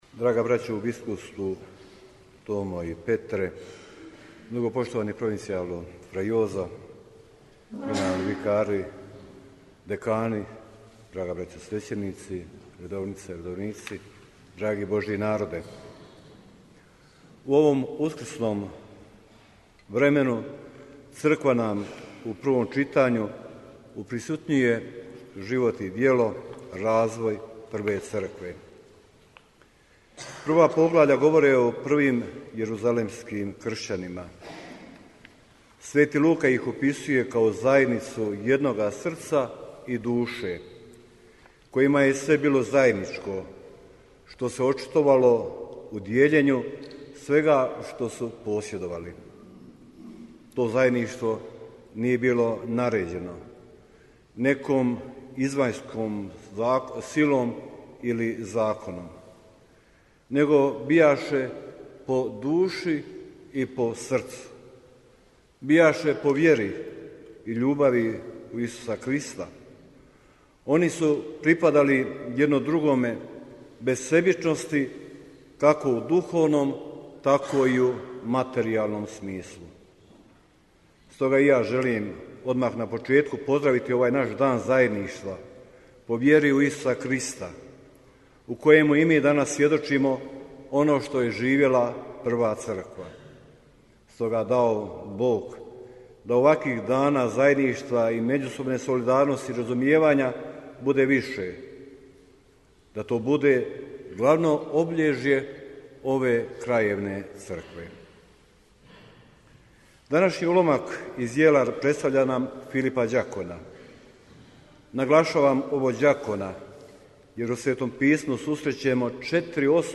Audio: Propovijed biskupa Majića u travničkoj crkvi na Misi u okviru Međudekanskog susreta - BANJOLUČKA BISKUPIJA
U okviru 11. Međudekanskog susreta Bosne i Hercegovine, Euharistijsko slavlje, u četvrtak 18. travnja 2024. u crkvi Nadbiskupijskog sjemeništa „Petar Barbarić“ posvećenoj sv. Alojziju Gonzagi u Travniku, predvodio je predsjednik Biskupske konferencije Bosne i Hercegovine mons. Tomo Vukšić, nadbiskup metropolit vrhbosanski i apostolski upravitelj Vojnog ordinarijata u BiH, u zajedništvu s biskupom mostarsko-duvanjskim i apostolskim upraviteljem trebinjsko-mrkanskim mons. Petrom Palićem i biskupom banjolučkim Željkom Majićem te uz koncelebraciju 31 svećenika.